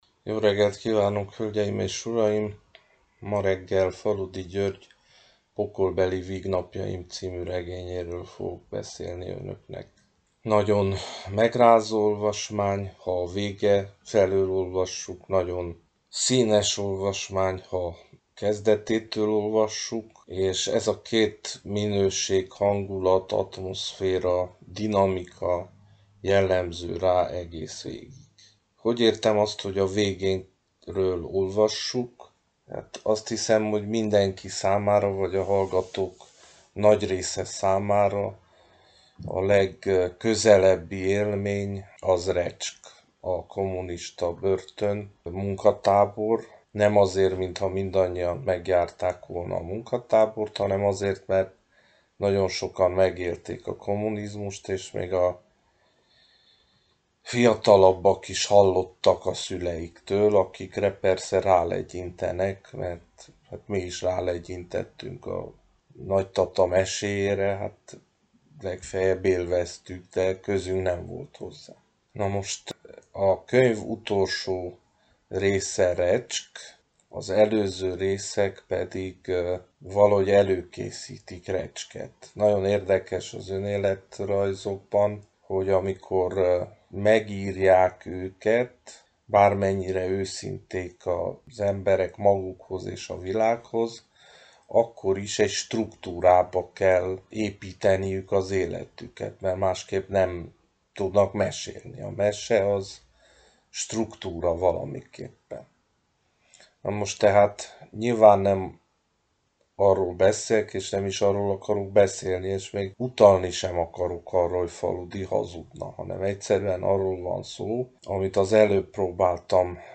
Klasszikus és kortárs művek egyaránt helyet kapnak ezekben az ajánlókban, ahogyan a magyar és a világirodalom palettájáról is segít nekünk válogatni az irodalmár.